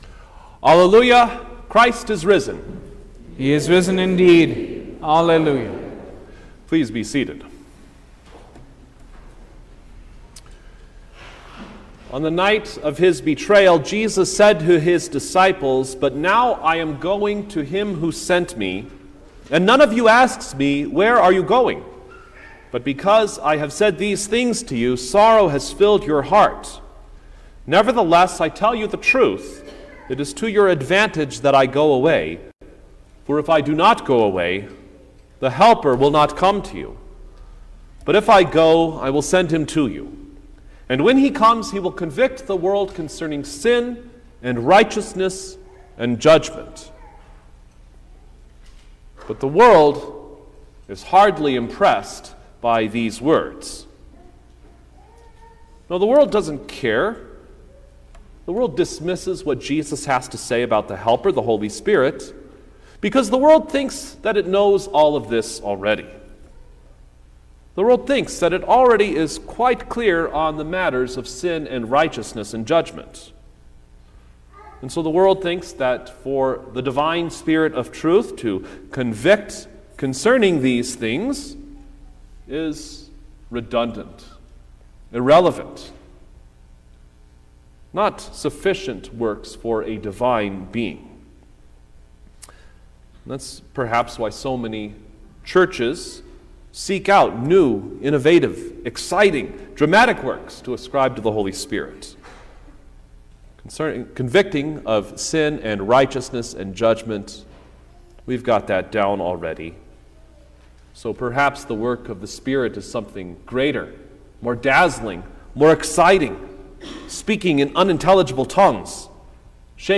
May-7_2023_Fifth-Sunday-of-Easter_Sermon-Stereo.mp3